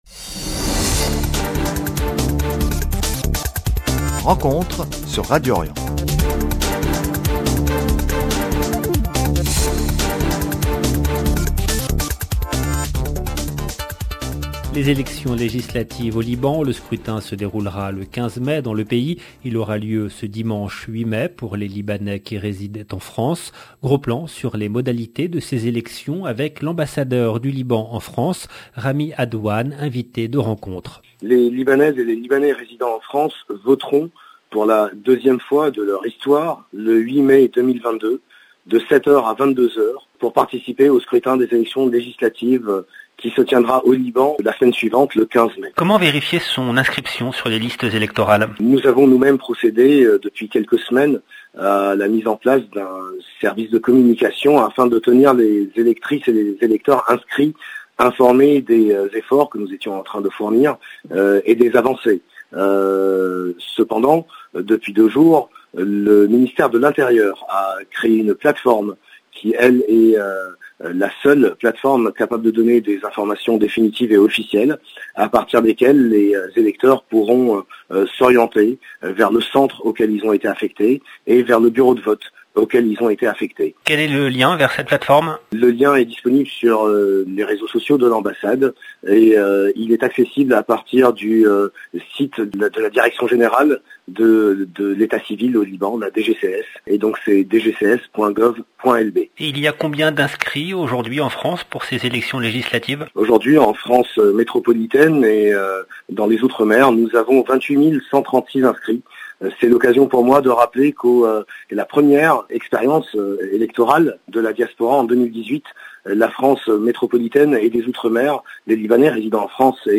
RENCONTRE avec RAMY ADWAN AMBASSADEUR DU LIBAN SUR ORGANISATION VOTE LEGISLATIVES
Gros plan sur les modalités de ces élections avec l’ambassadeur du Liban en France, Rami Adwan invité de Rencontre. 0:00 13 min 19 sec